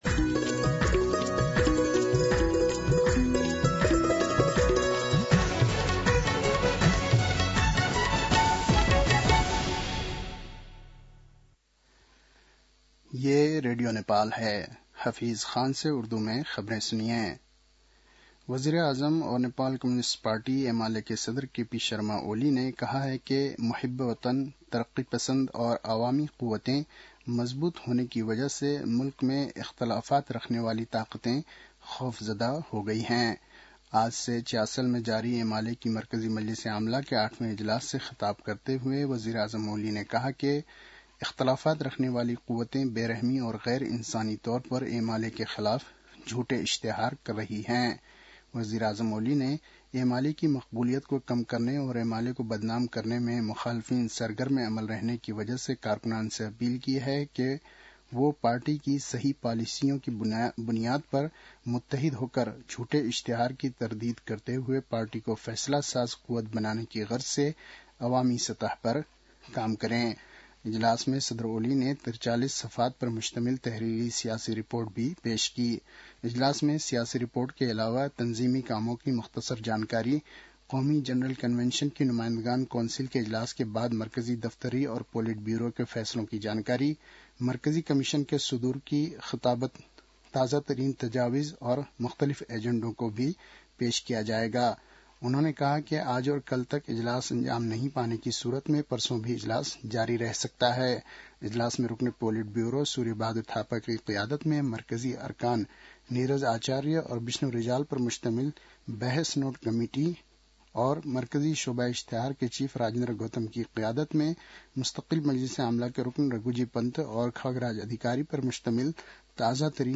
An online outlet of Nepal's national radio broadcaster
उर्दु भाषामा समाचार : २२ पुष , २०८१